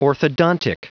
Prononciation du mot orthodontic en anglais (fichier audio)
Prononciation du mot : orthodontic
orthodontic.wav